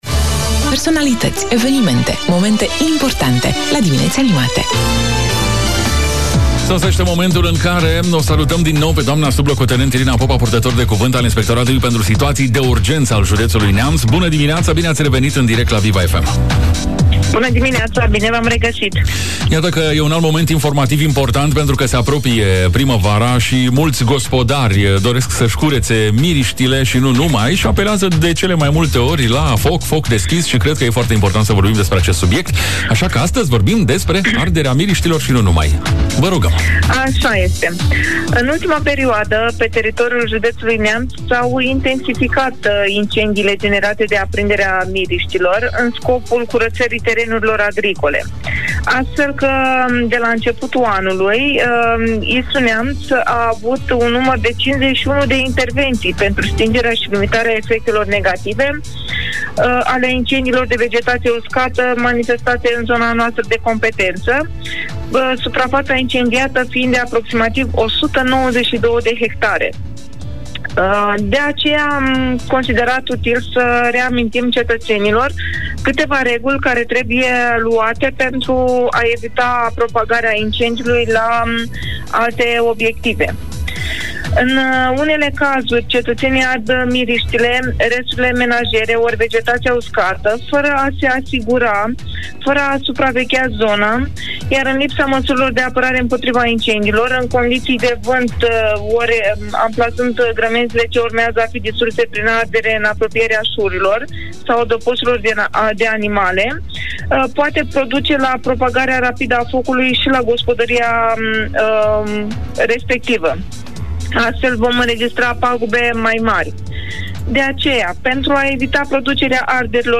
Arderile de vegetație uscată reprezintă un real pericol dacă nu sunt realizate conform normelor specifice. Informații suplimentare ne-a oferit în direct prin telefon la Dimineți Animate pe Viva FM Neamț